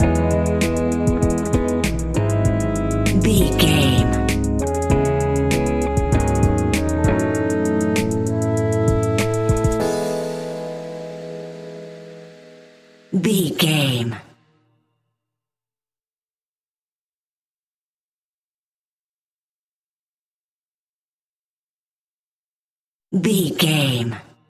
Ionian/Major
instrumentals
chilled
laid back
groove
hip hop drums
hip hop synths
piano
hip hop pads